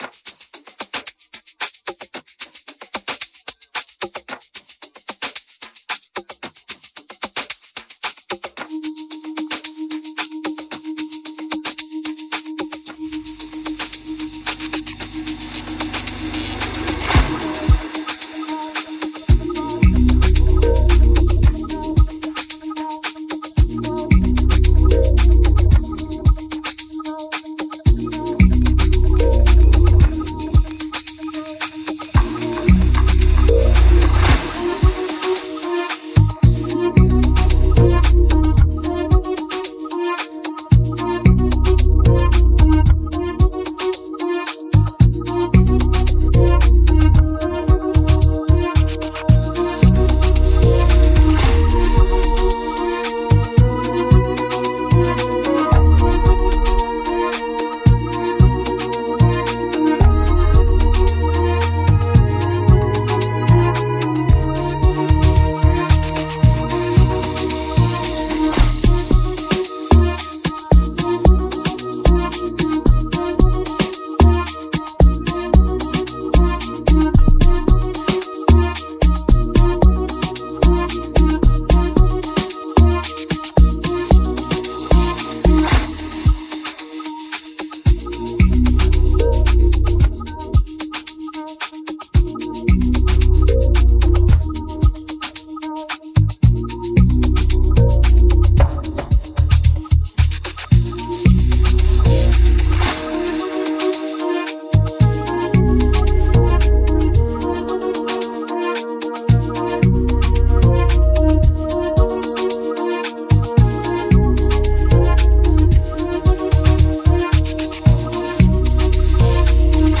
Afrobeats
amapiano